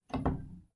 book-take01.ogg